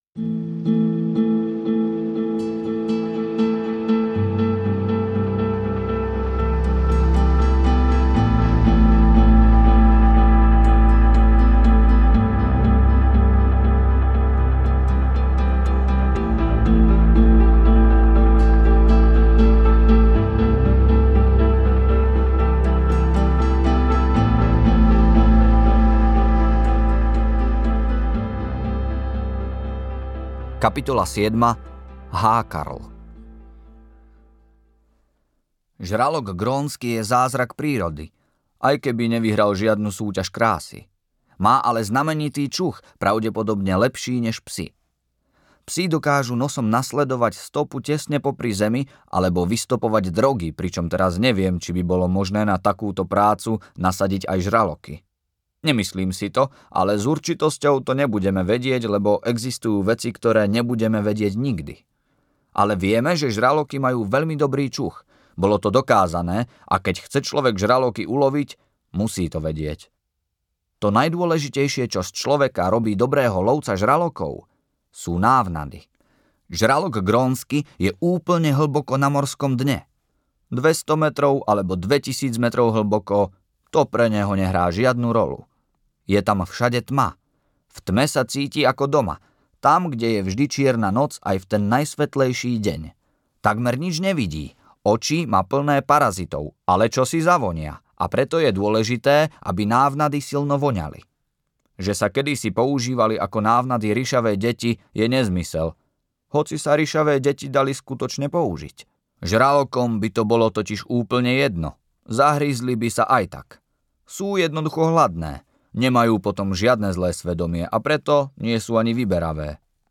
Kalmann audiokniha
Ukázka z knihy